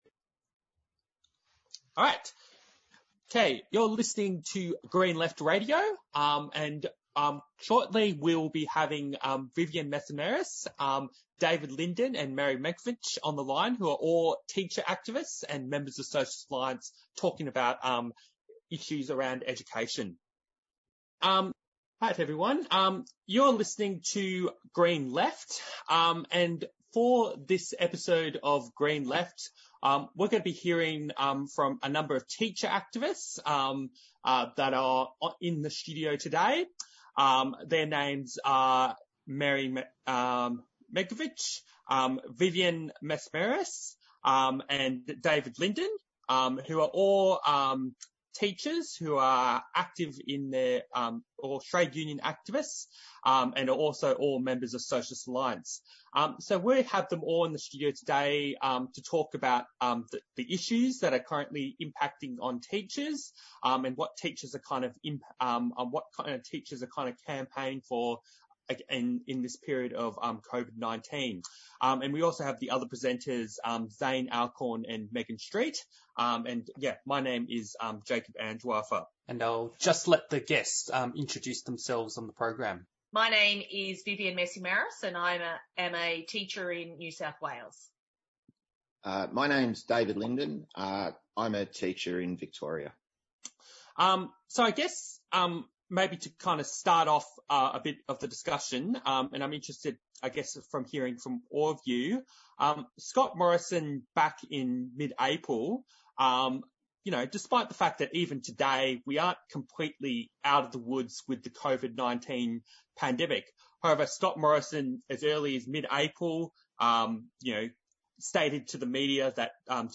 Interviews & Discussions